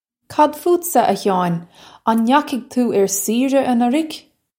Pronunciation for how to say
Kad footsa, a Hyaw-in? Un nya-hig too urr seera anurrig?
This is an approximate phonetic pronunciation of the phrase.